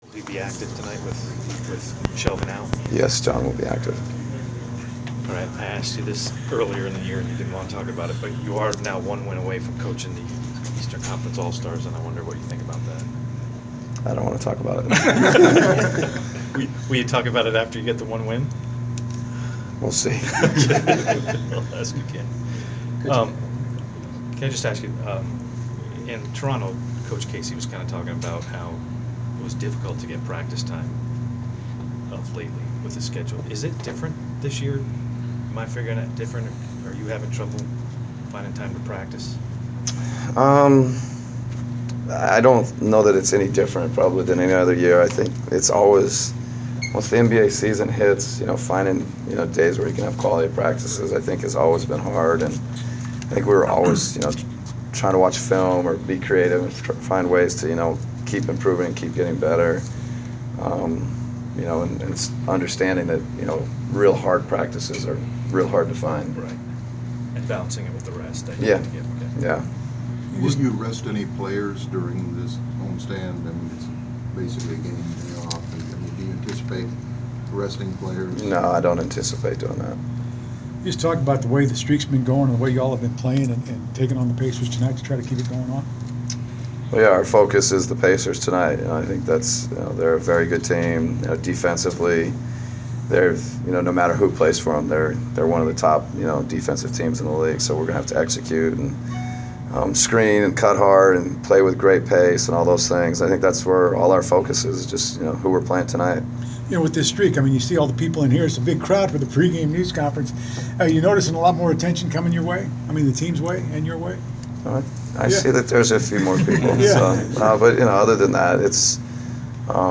Inside the Inquirer: Pregame presser with Atlanta Hawks’ head coach Mike Budenholzer (1/21/15)
We attended the pregame presser of Atlanta Hawks’ head coach Mike Budenholzer before his team’s home contest against the Indiana Pacers on Jan. 21. Topics included the team’s play towards the All-Star game, facing the Pacers, the extra media attention the Hawks have received, play of the bench and contributions of Al Horford.